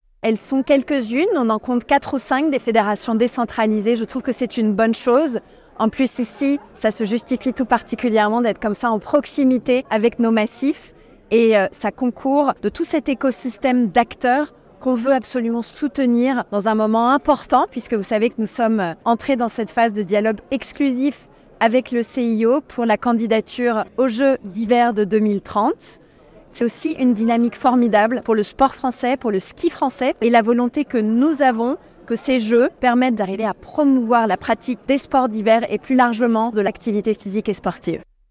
ITA Amélie Oudéa Castéra 3 – Inauguration siège FFS (38’’)